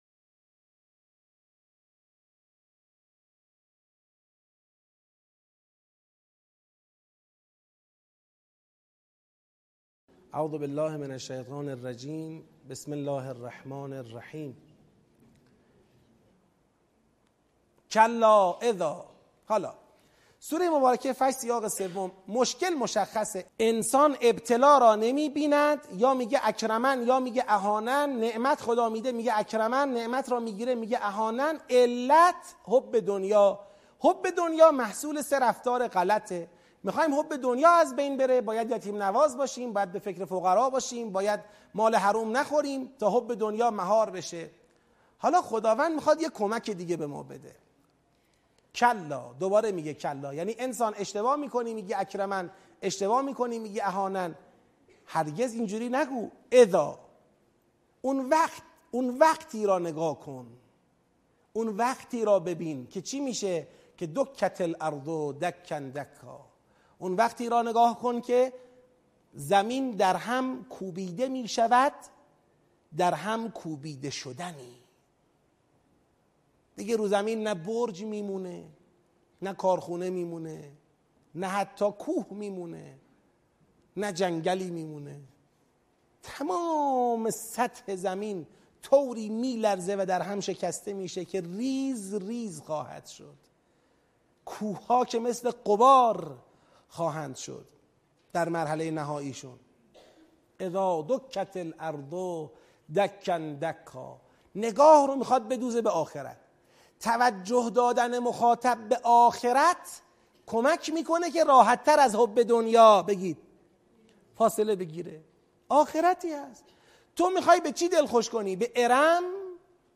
آموزش تدبر در سوره فجر - بخش چهارم